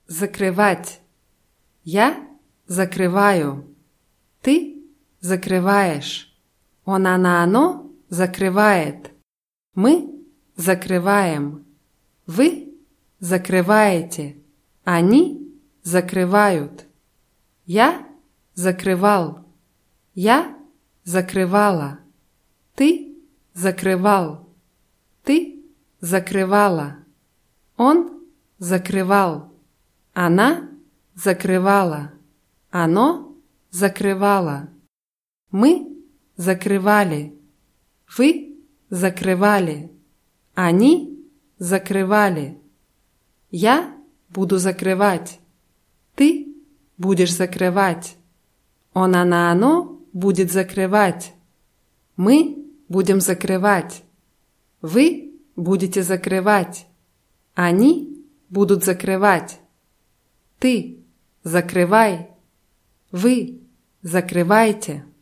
закрывать [zakrywátʲ]